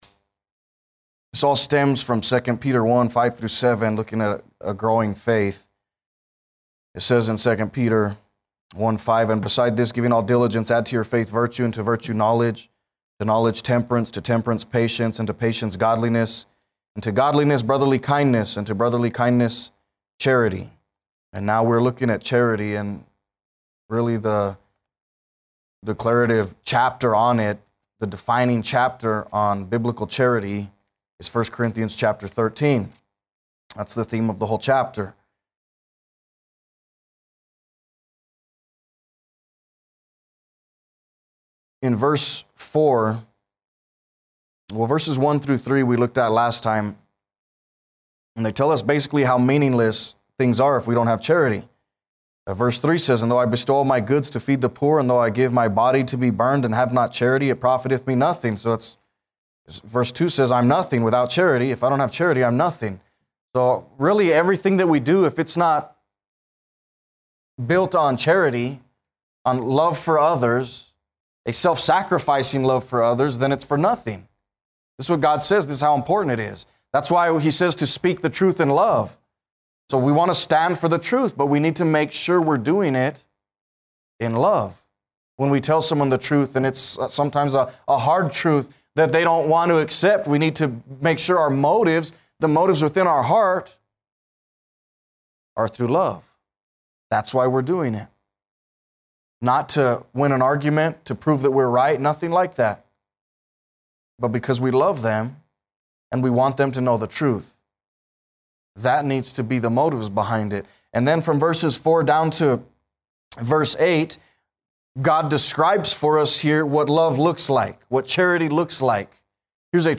A message from the series "What is a Disciple?."